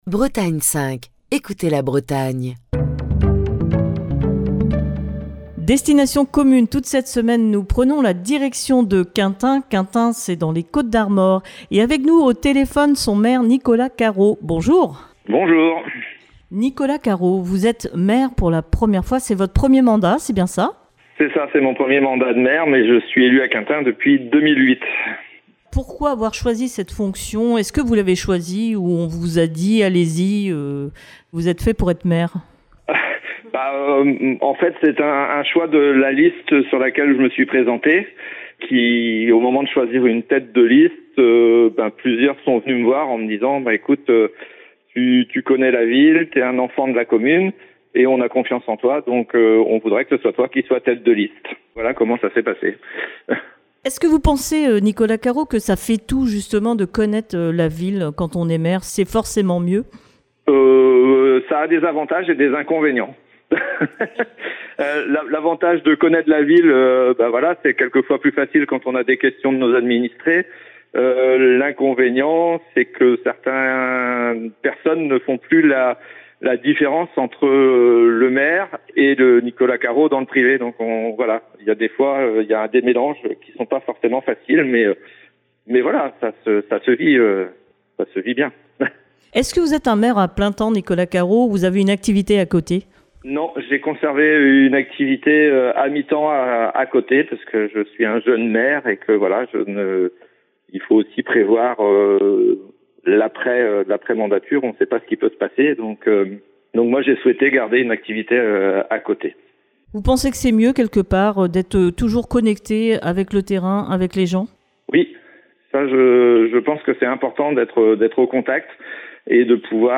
est au téléphone avec le maire de Quintin